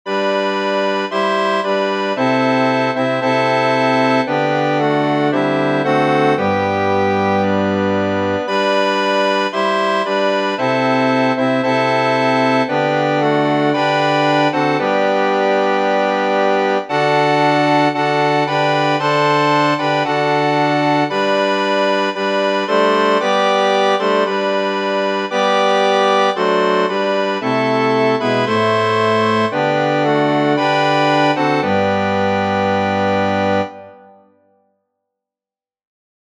Órgano